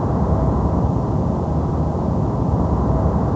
Platform.wav